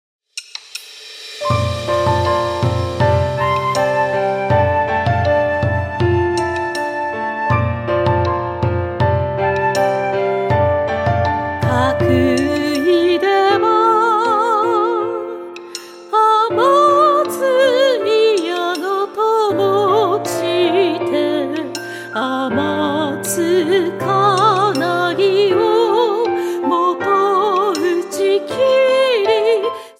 ８つの曲を通して、澄んだ歌声と優しい響きが、心に静けさと力を届けます。